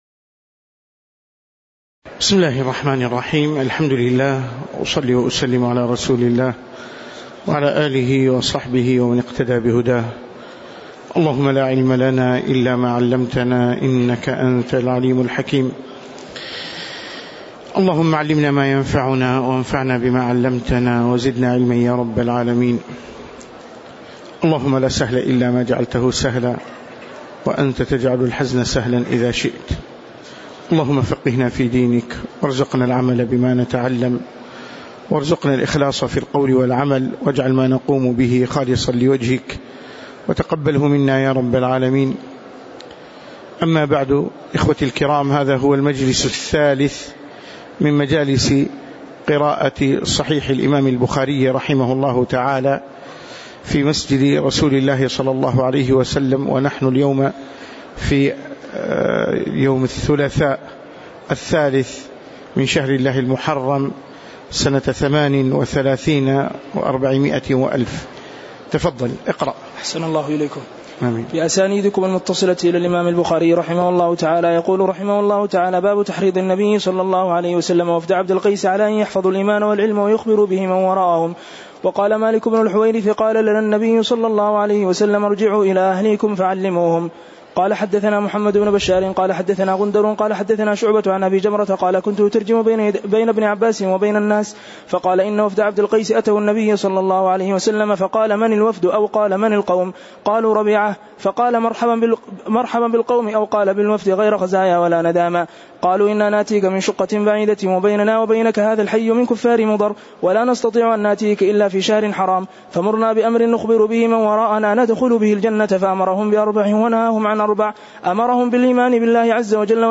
تاريخ النشر ٣ محرم ١٤٣٨ هـ المكان: المسجد النبوي الشيخ